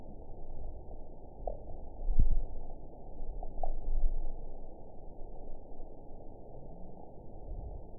event 921562 date 11/30/24 time 01:11:04 GMT (5 months, 1 week ago) score 7.80 location TSS-AB03 detected by nrw target species NRW annotations +NRW Spectrogram: Frequency (kHz) vs. Time (s) audio not available .wav